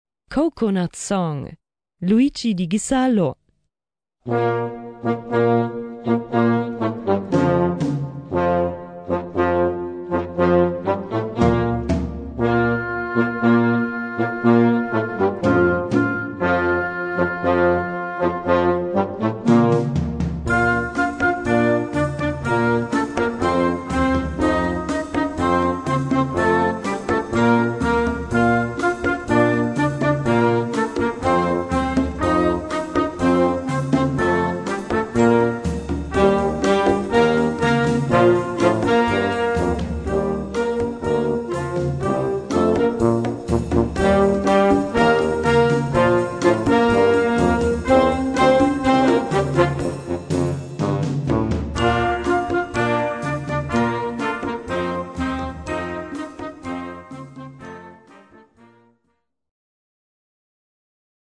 Gattung: Worksong
Besetzung: Blasorchester